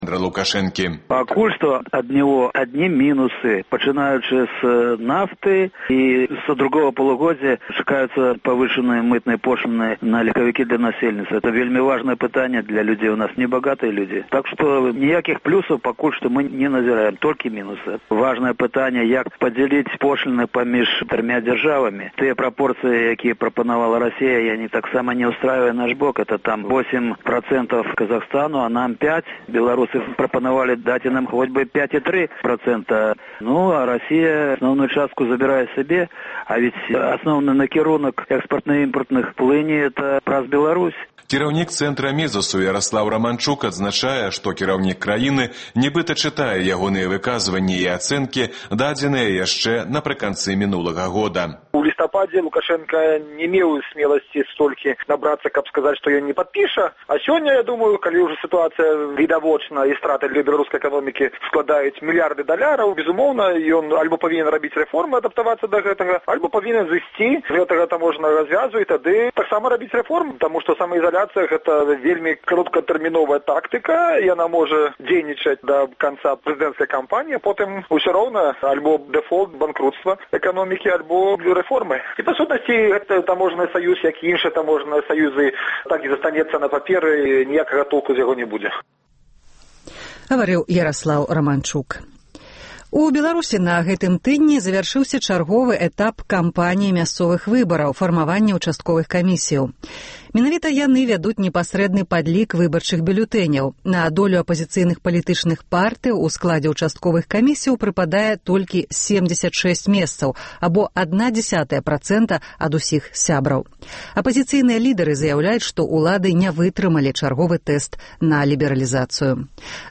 Тыднёвы агляд званкоў ад слухачоў Свабоды